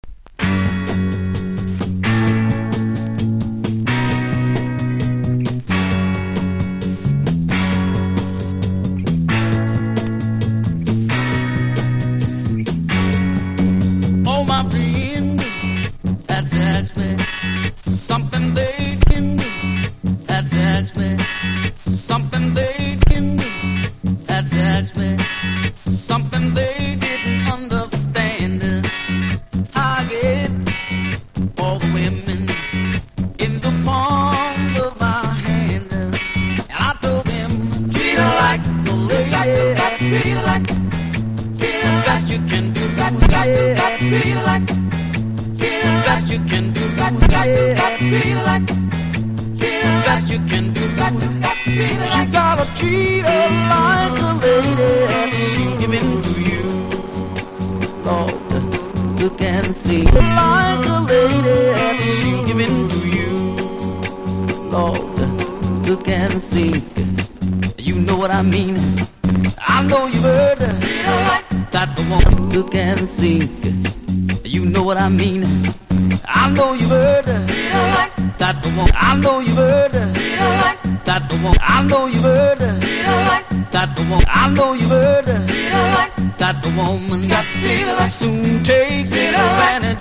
シャープなバッキングが気持ち良いのブリッとしたベースも渋い